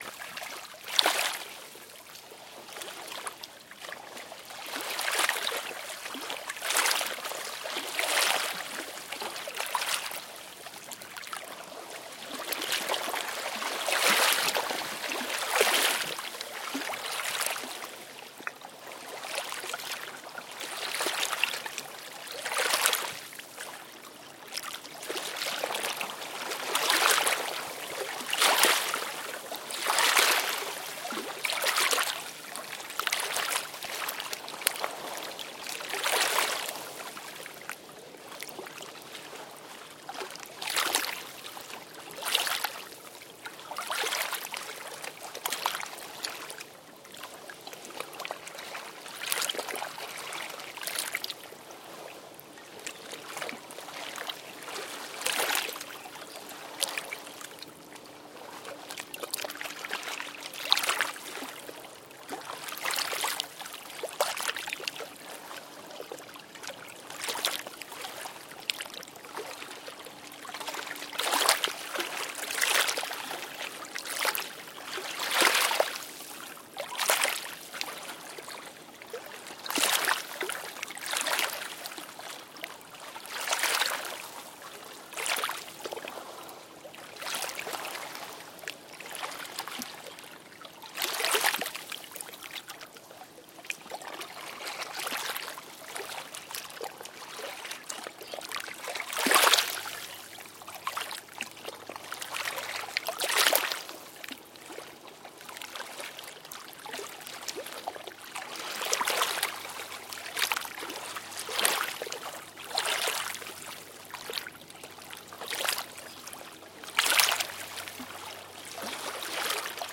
Звуки воды
На этой странице собраны разнообразные звуки воды: журчание ручья, шум прибоя, капли дождя и плеск водопада.